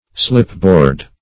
Slipboard \Slip"board`\, n. A board sliding in grooves.